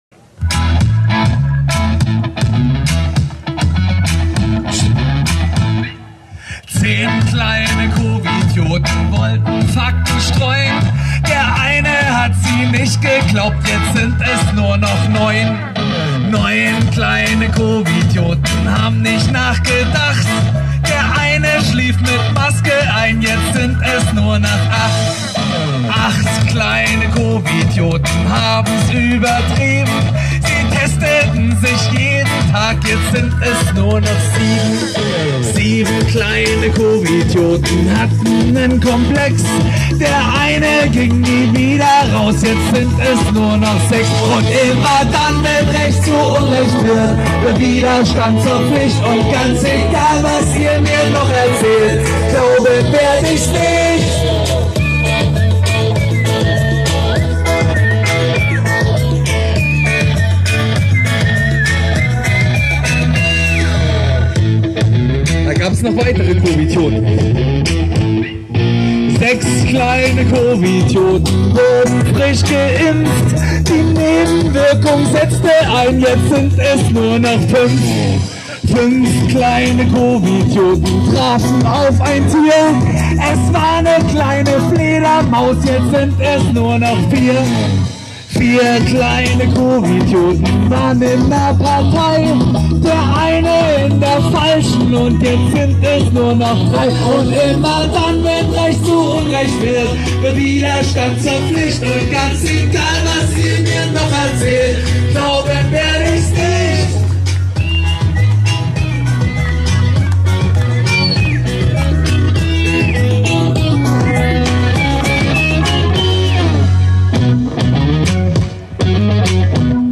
dieser sollte sofort ins Studio und das professionell aufnehmen... :)